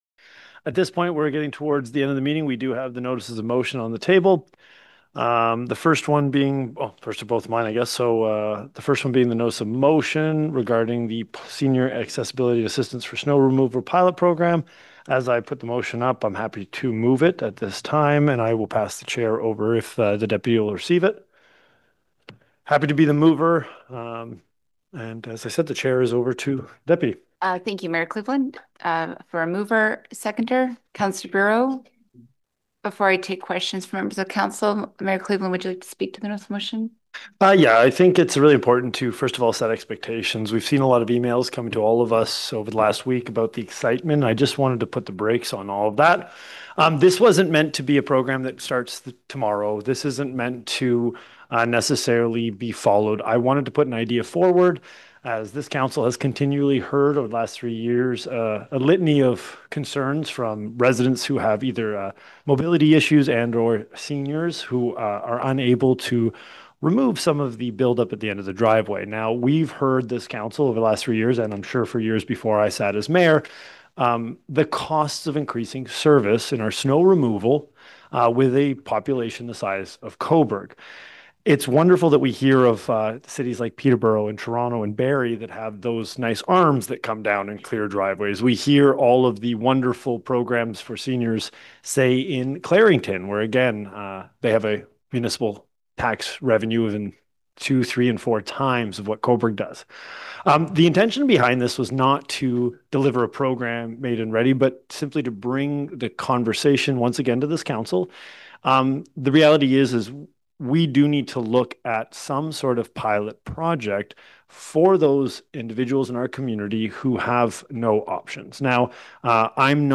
Mayor Lucas Cleveland gave the green light to the idea discussed at the regular council meeting, noting a community need expressed by seniors and people with mobility challenges.